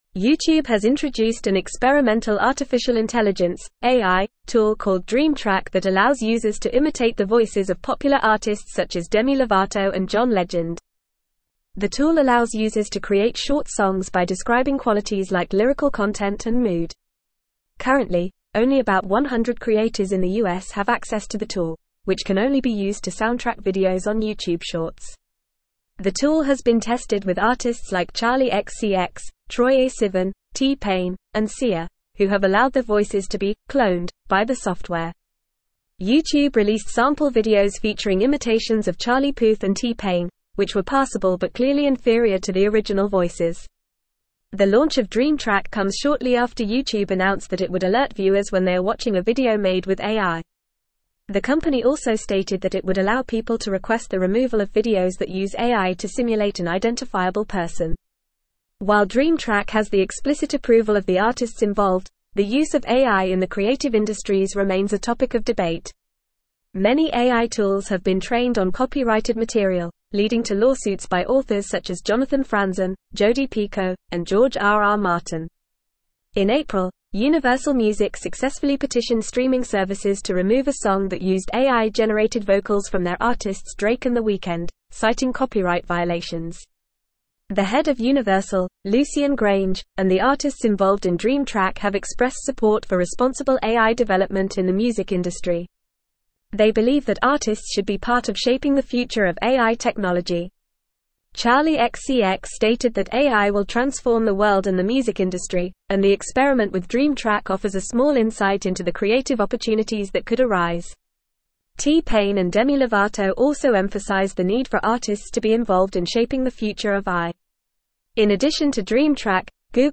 Fast
English-Newsroom-Advanced-FAST-Reading-YouTube-Unveils-Dream-Track-AI-Tool-Imitates-Artists-Voices.mp3